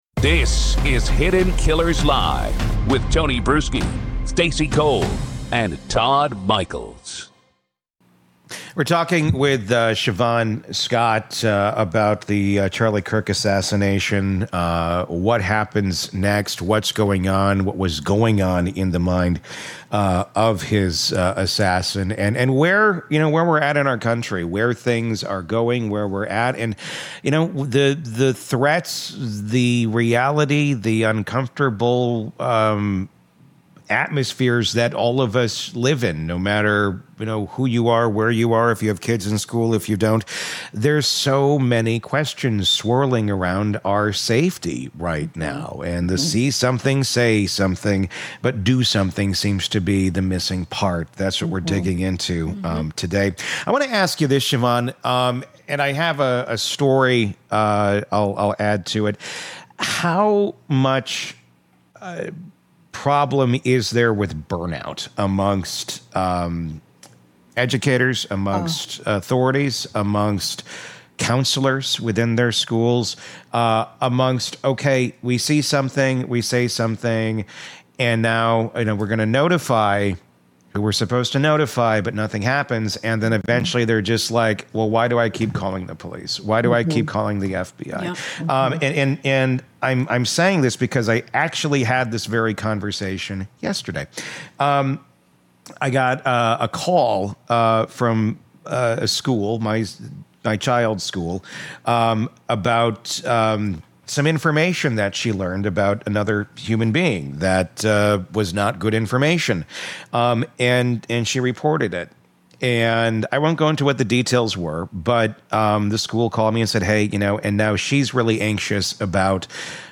This episode pulls back the curtain on the psychology of targeted violence—and why so many communities only connect the dots when it’s already too late. 🔔 Subscribe for more true crime interviews, forensic breakdowns, and expert-driven analysis from inside the nation’s most disturbing cases.